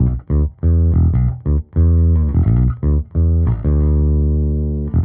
Index of /musicradar/dusty-funk-samples/Bass/95bpm
DF_JaBass_95-E.wav